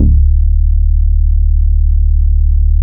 BASS 2.wav